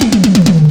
02_09_drumbreak.wav